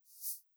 LittleSwoosh1a.wav